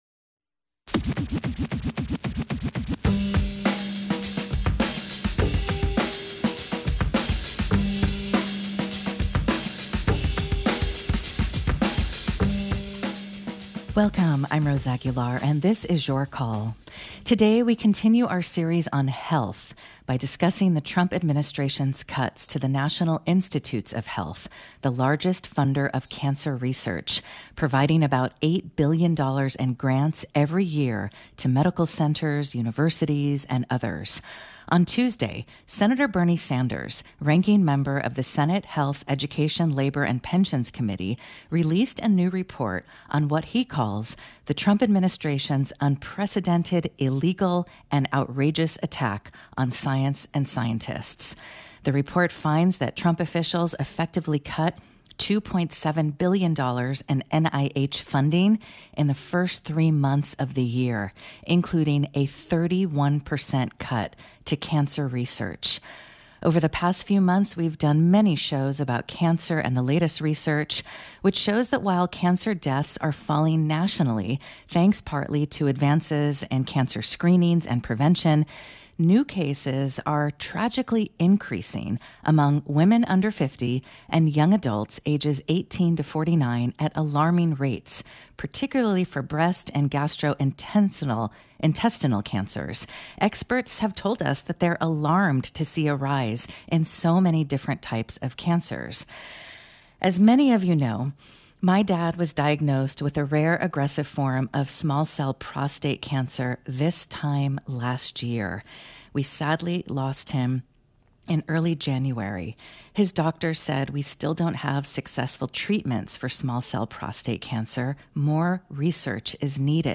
KALW's call-in show: Politics and culture, dialogue and debate.